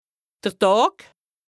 L’alsacien regroupe plusieurs variantes dialectales du Nord au Sud de l’Alsace.
Nous avons tenté d’être représentatifs de cette diversité linguistique en proposant différentes variantes d’alsacien pour chaque lexique, à l’écrit et à l’oral.